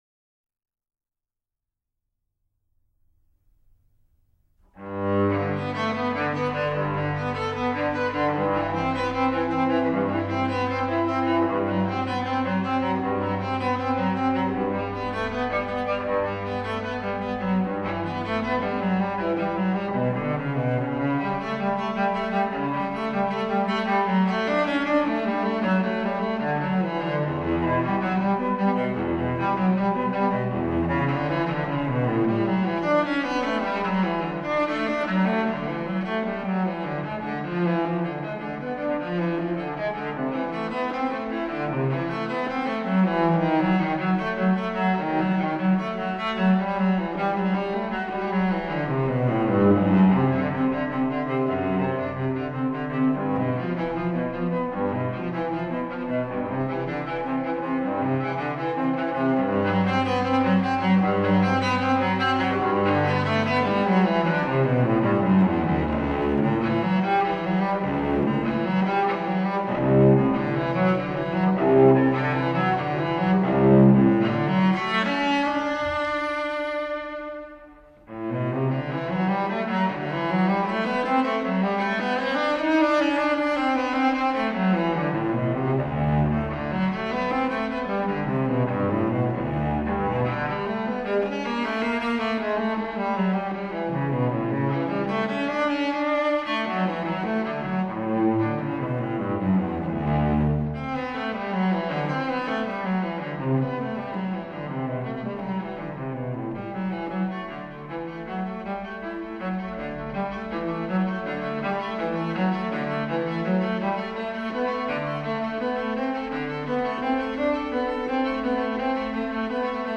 音乐类型：Classical
cello